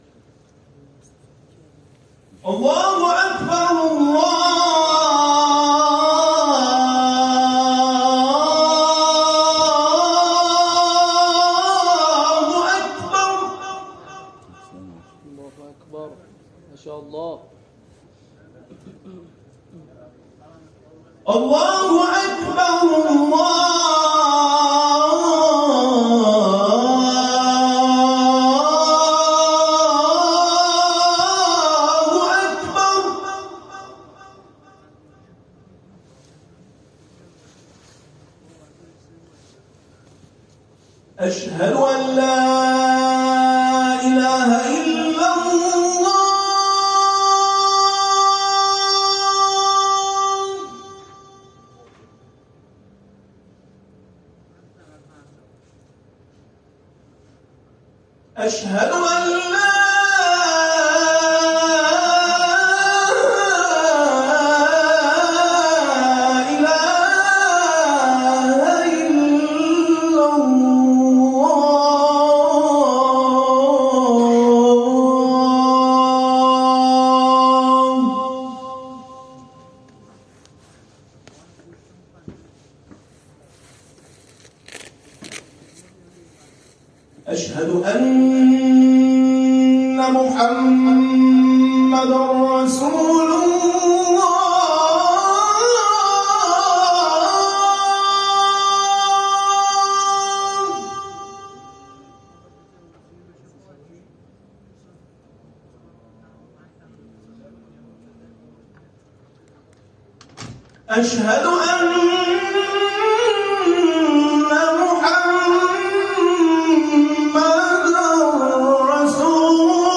مؤذن نمونه دل‌ها را مجذوب شعار اذان می‌کند + صوت اذان